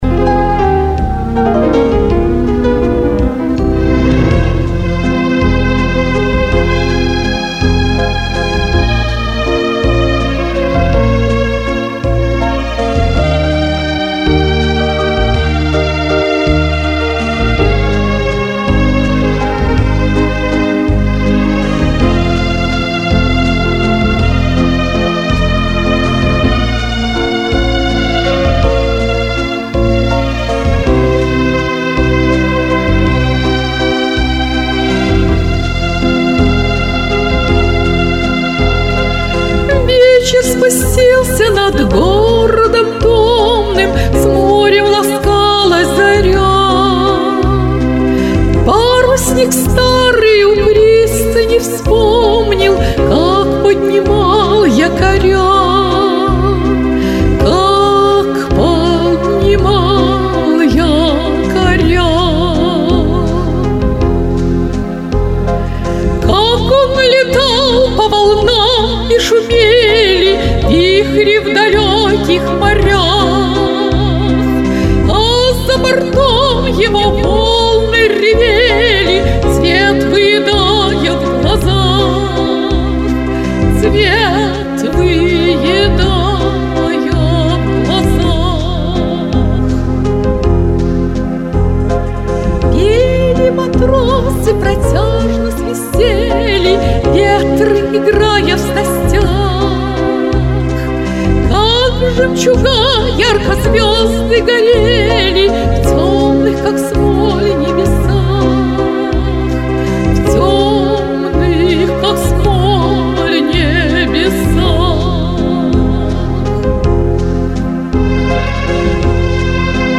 Пожалуйста, дайте оценку "демке".
Я автор и мелодии, и текста, и даже характер звучания предложил синтезаторщику, а он наиграл.
Пожалуйста, вокал и аранжировку не обсуждайте, так как их пока нет.